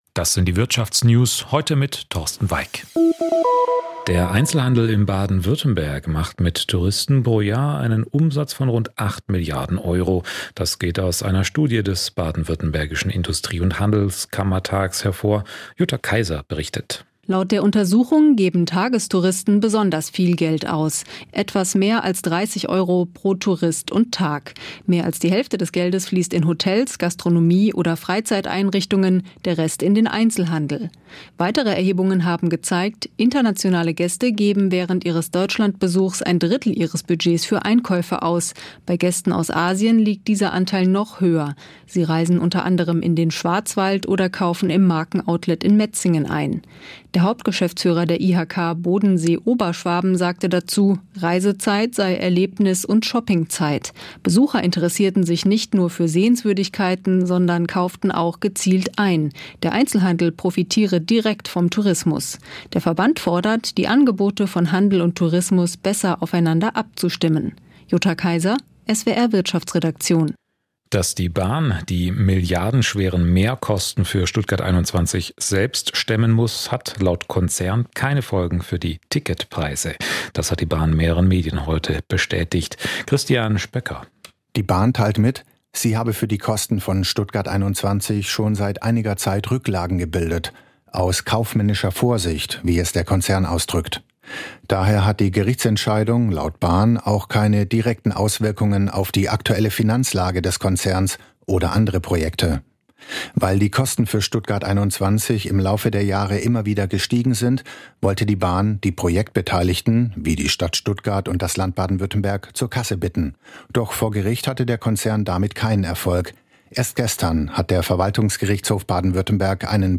… continue reading 104 odcinków # Business News # Nachrichten # Südwestrundfunk # SWR Aktuell # Wirtschaft # Unternehmer # Börse # Beschäftigung # Arbeitnehmer # Arbeitgeber # Konzerne # Soziales # Gewerkschaft